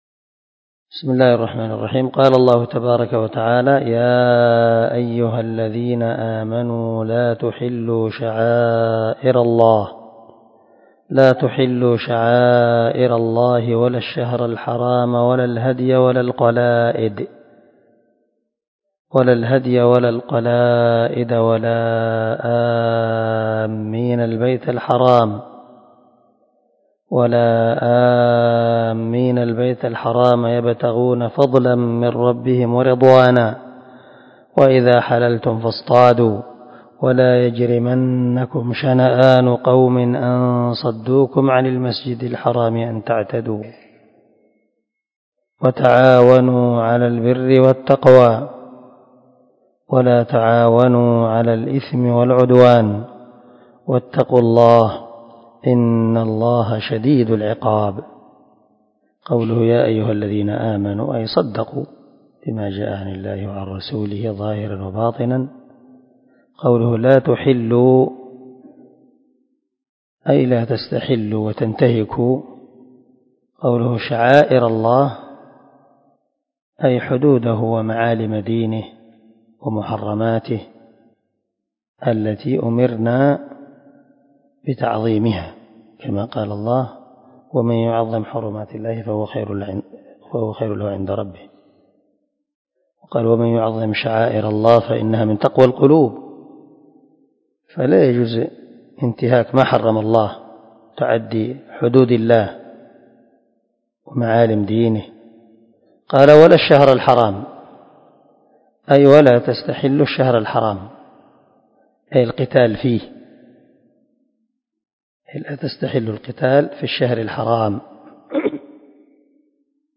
335الدرس 2 تفسير آية ( 2 ) من سورة المائدة من تفسير القران الكريم مع قراءة لتفسير السعدي
دار الحديث- المَحاوِلة- الصبيحة.